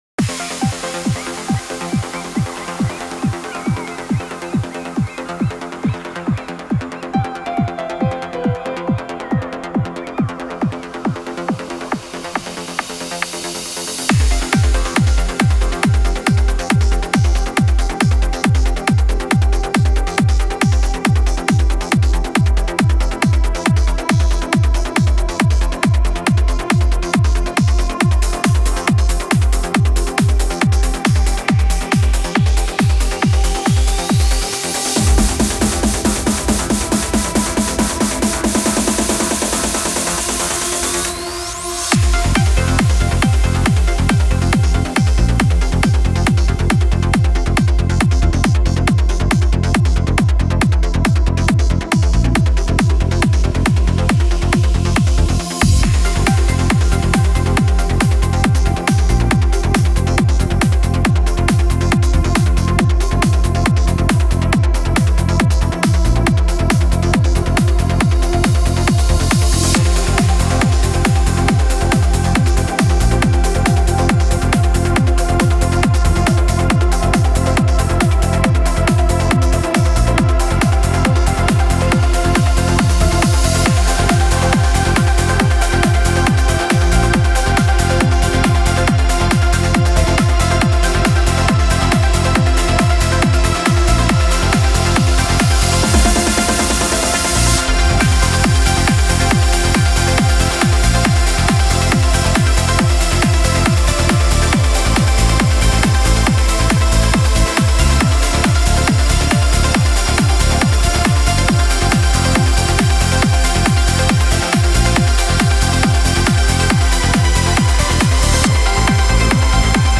Стиль: Uplifting Trance